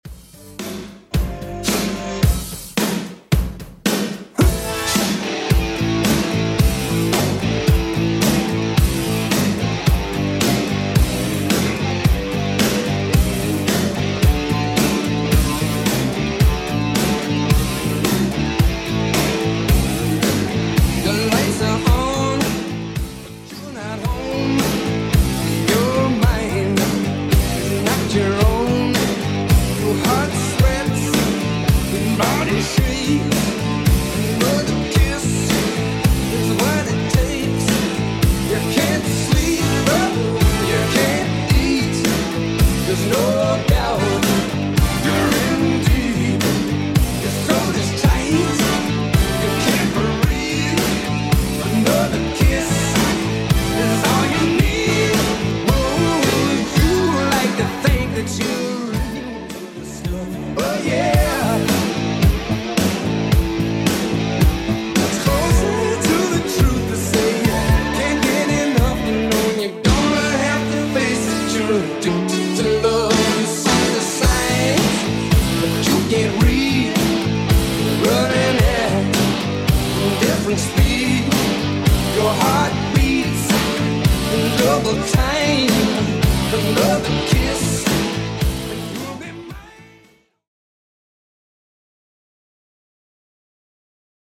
Genre: 80's Version: Clean BPM: 122